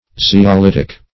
Search Result for " zeolitic" : The Collaborative International Dictionary of English v.0.48: Zeolitic \Ze`o*lit"ic\, a. Of or pertaining to a zeolite; consisting of, or resembling, a zeolite.